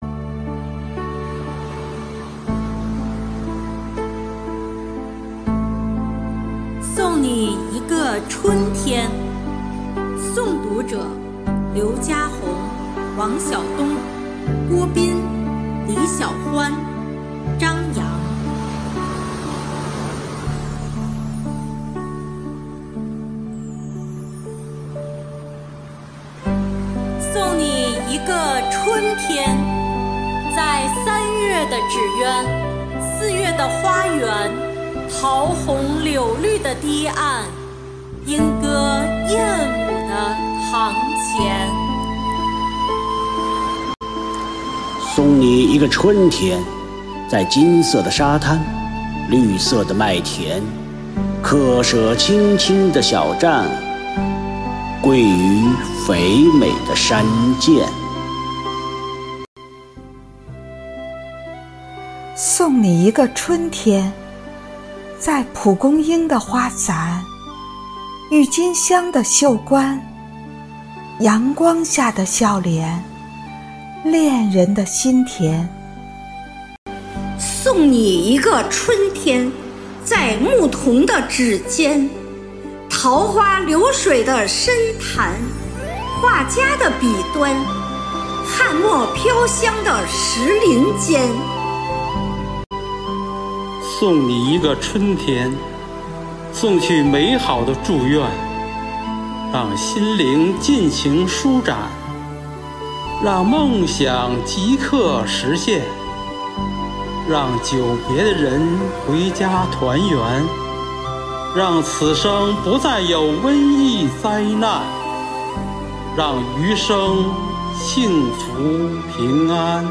“四月，我们和春天在一起”主题云朗诵会
合诵《送你一个春天》
生活好课堂幸福志愿者诵读心声朗读服务（支）队
《送你一个春天》合诵：诵读心声朗读支队.mp3